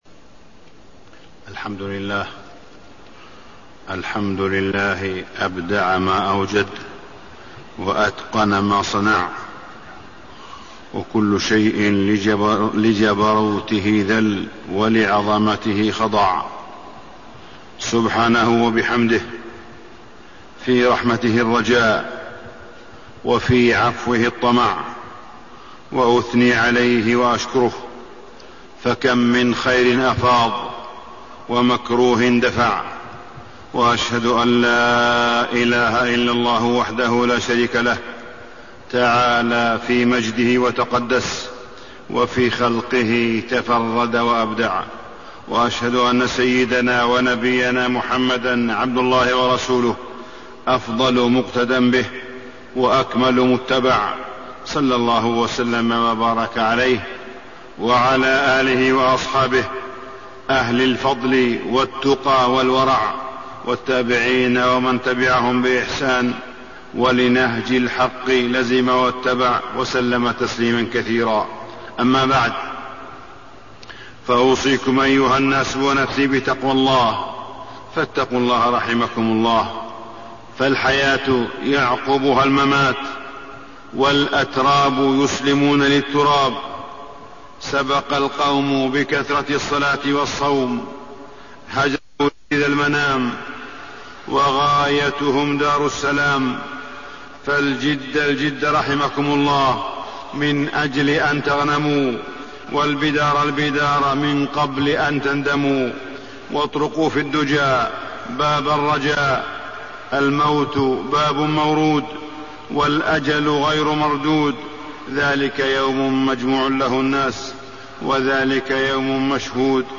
تاريخ النشر ١٨ رجب ١٤٣٣ هـ المكان: المسجد الحرام الشيخ: معالي الشيخ أ.د. صالح بن عبدالله بن حميد معالي الشيخ أ.د. صالح بن عبدالله بن حميد النظام والفوضى في حياة المسلم